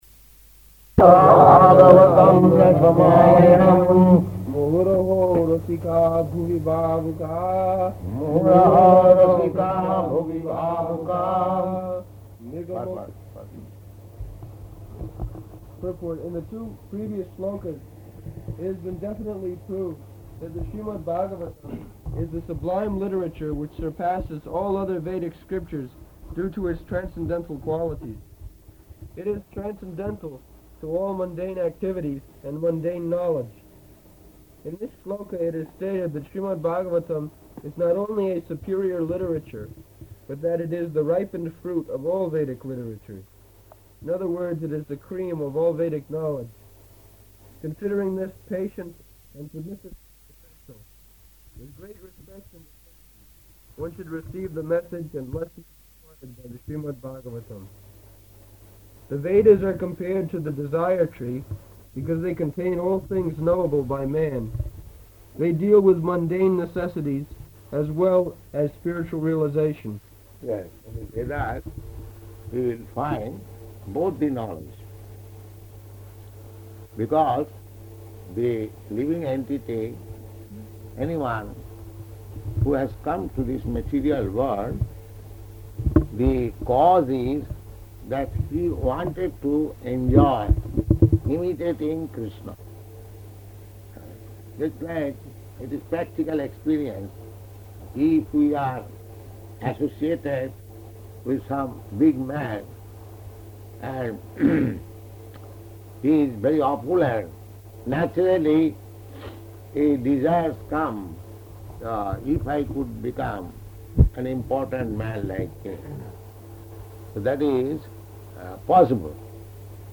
Location: London
[leading chanting of verse]